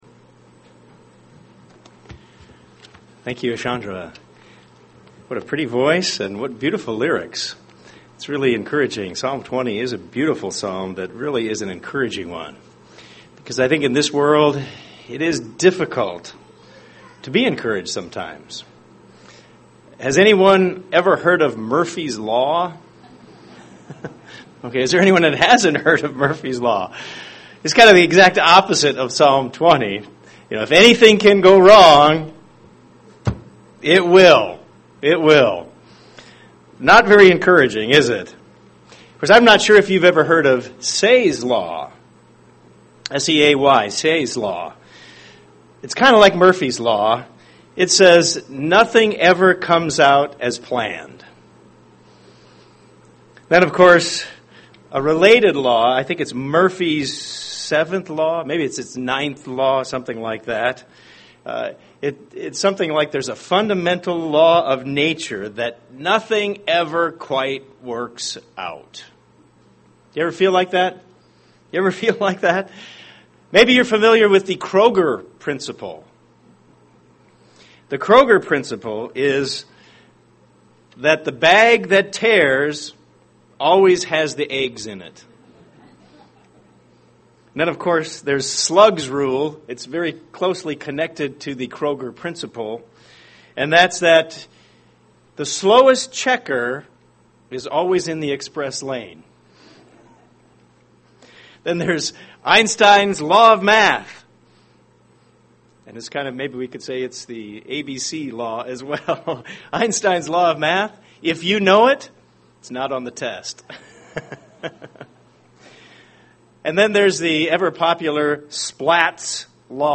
Examining Jeremiah UCG Sermon Transcript This transcript was generated by AI and may contain errors.